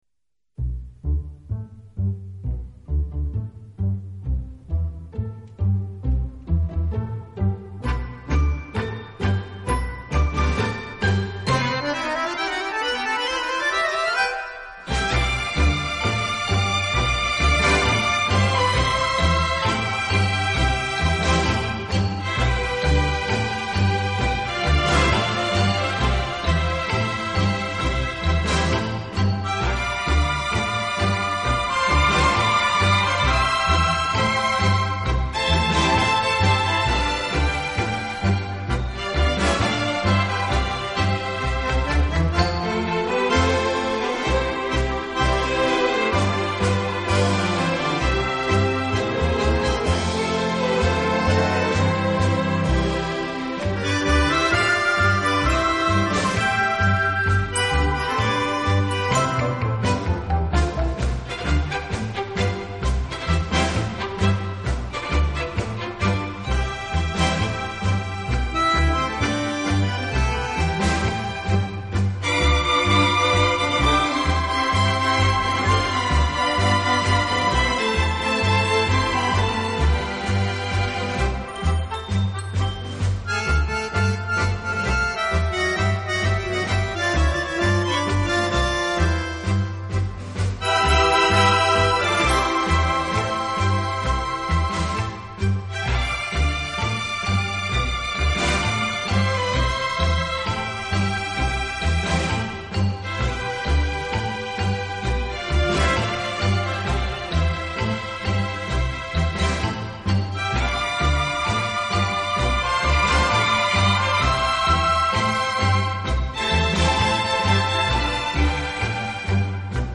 乐背景的不同，以各种乐器恰到好处的组合，达到既大气有力又尽显浪漫的效果。
乐队的弦乐柔和、优美，极有特色，打击乐则气度不凡，而手风琴、钢琴等乐器